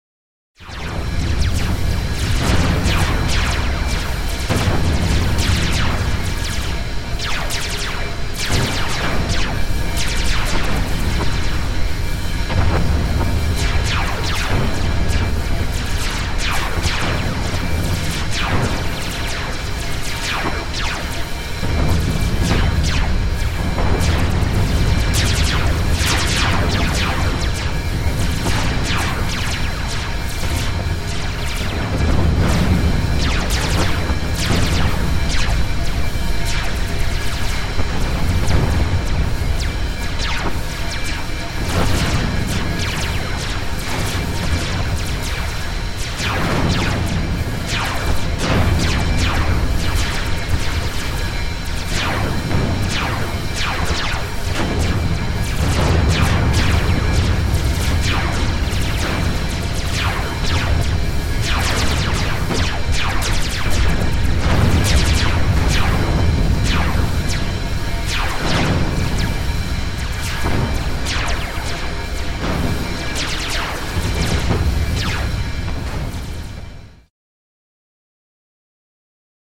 Звуки бластера
Звуки Звездных войн, перестрелка